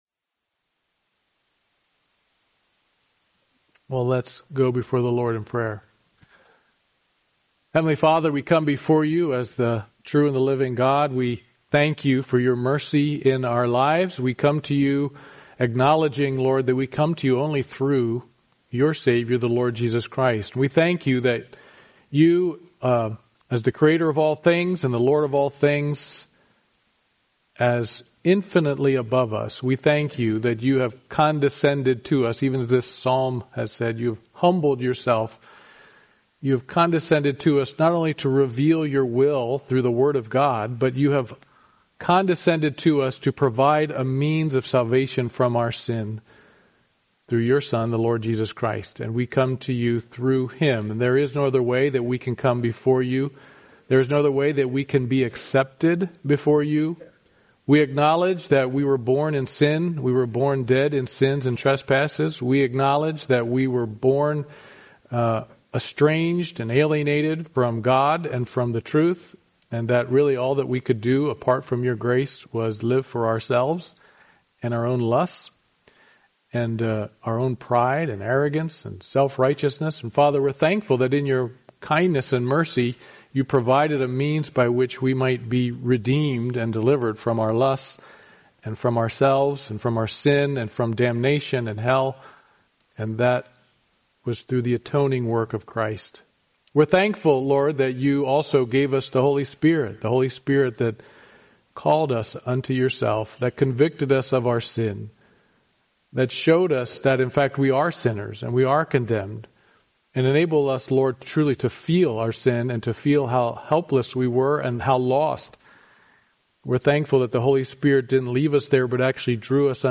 Job 21 Service Type: Sunday Morning Worship « Now to See the Work of God in the World Part 2 36 Sorrent